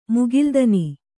♪ mugildani